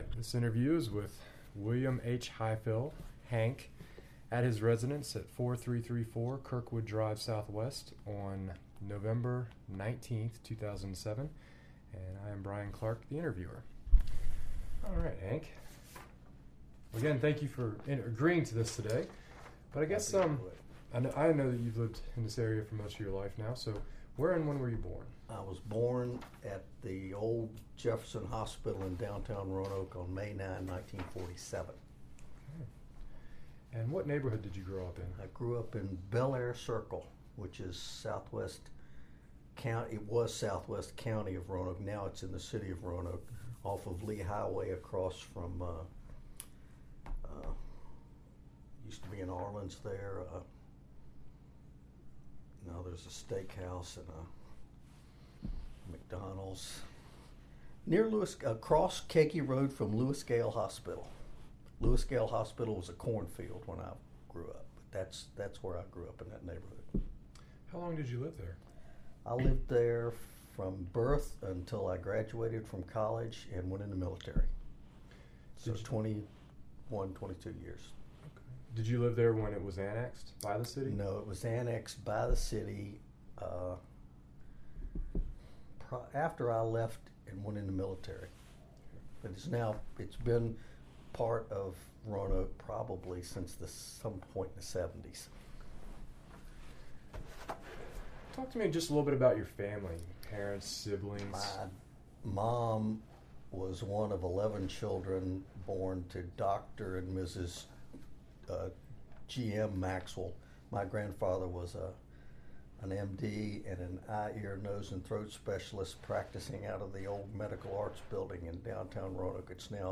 Location: Private residence
Neighborhood Oral History Project